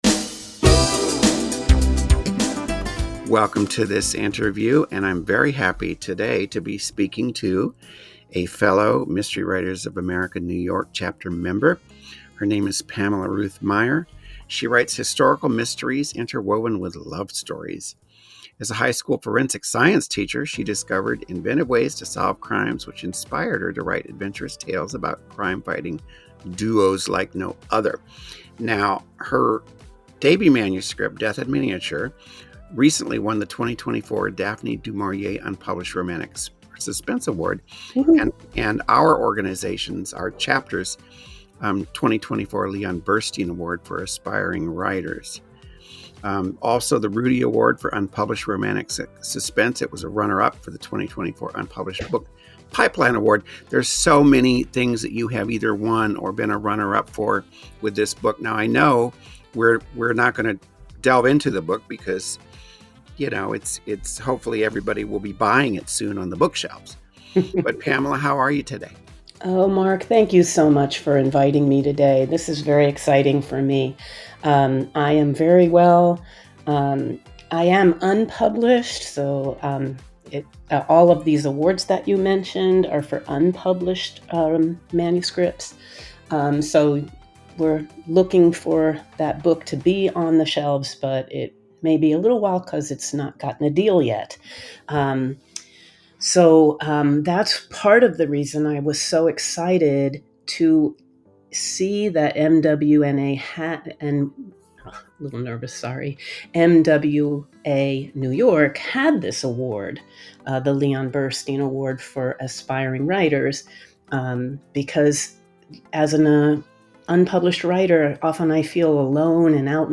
Audio Interview
She’s as delightful as her fiction, so fasten your headphones for this casual chat about her life, writing and hopes for the future.